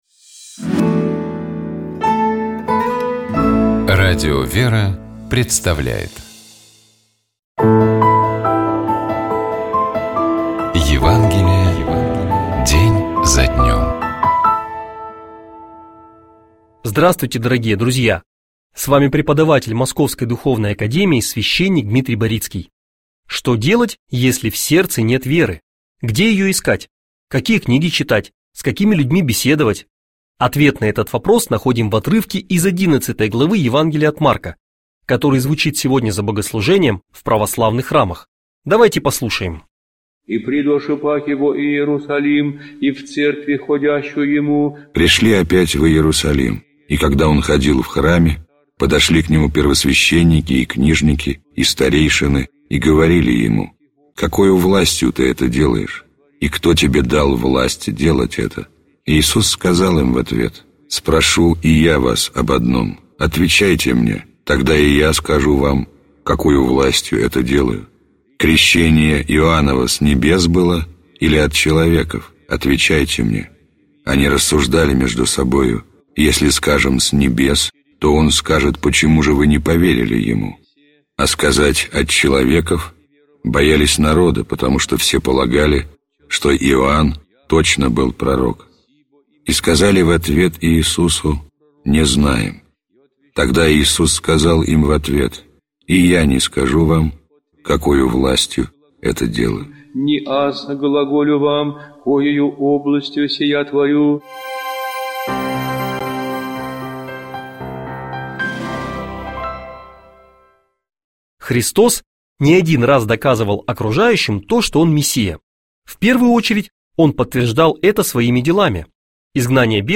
Читает и комментирует священник